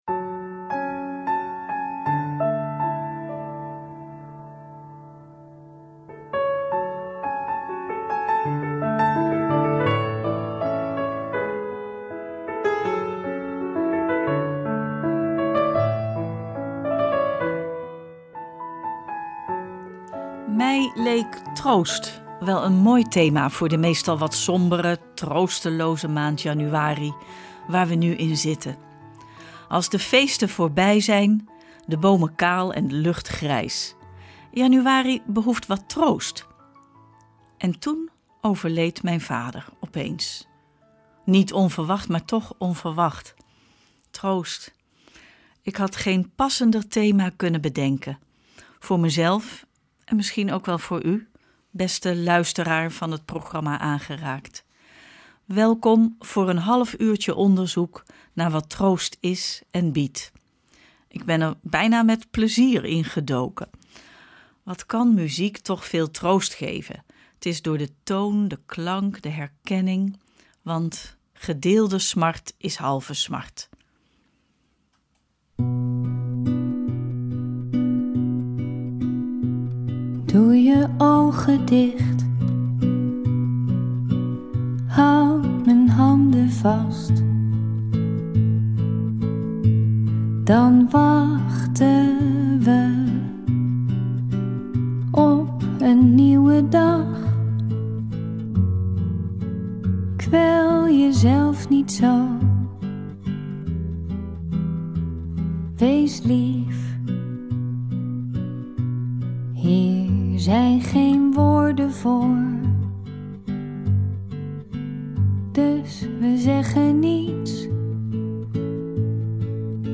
Maar hoe werkt troost, wat biedt troost, en hoe houden we het uit als we ontroostbaar zijn ? Daarbij klinken teksten en muziek die optillen en misschien, toch een beetje, zalf op de wonde zijn.